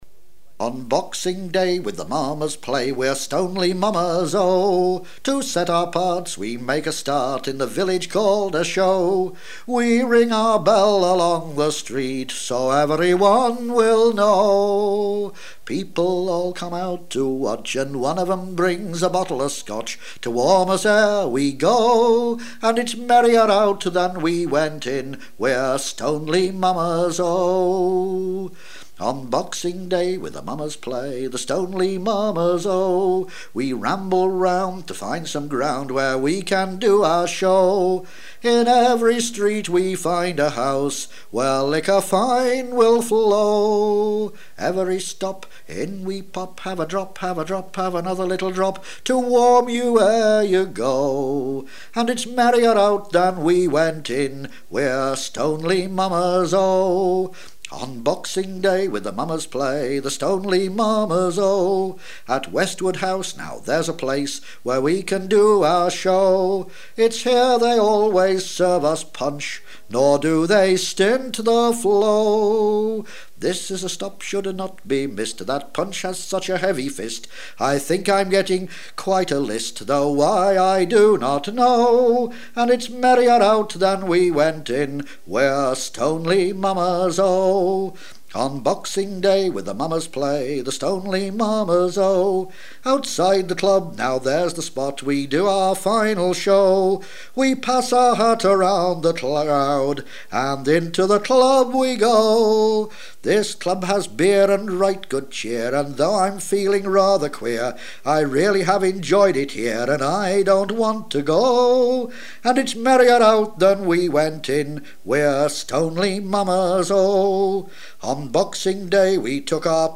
As a multi-talented performer with a fine singing voice and writer of lyrics (tended to utilise existing tunes), he produced the song "Stoneleigh Mummers Oh" which is sung to the tune of Old King Cole.